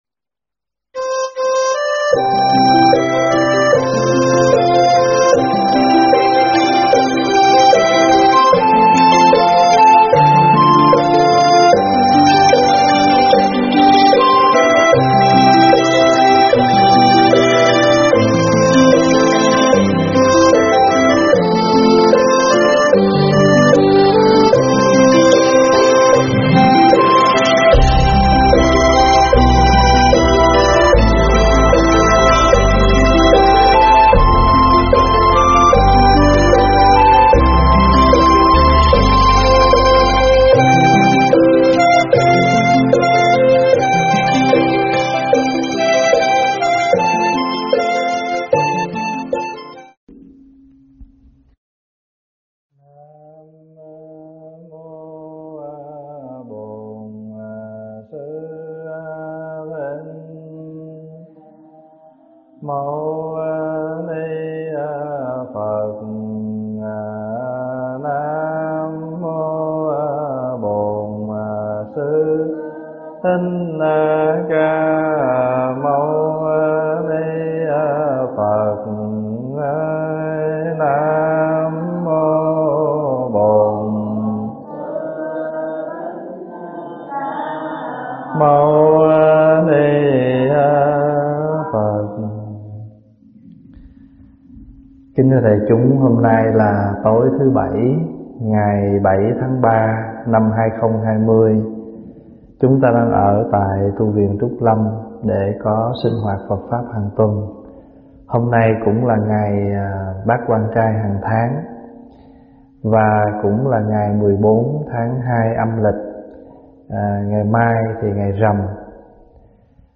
Thuyết pháp Xe Báu Đại Thừa 3
giảng tại Tv.Trúc Lâm